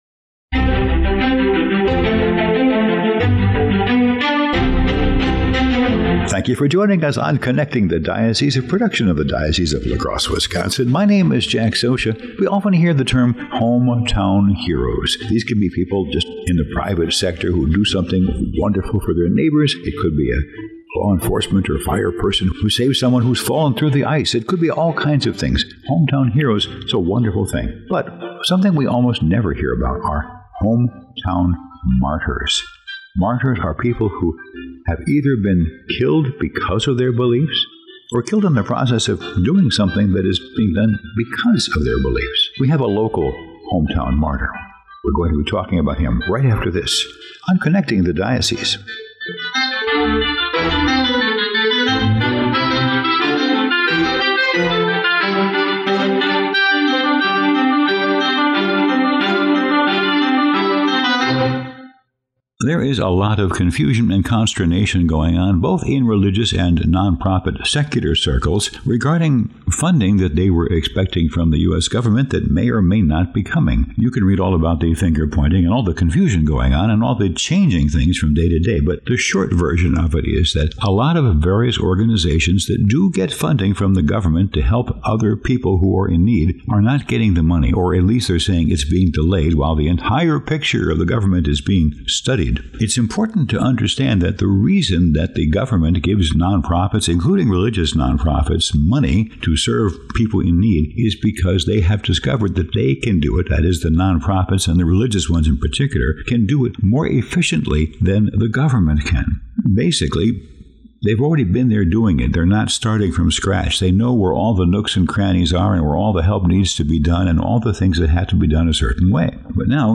"Connecting the Diocese," a radio show from the Diocese of La Crosse, Wisconsin, aired a Feb. 15 episode on "hometown martyr" Blessed James Miller.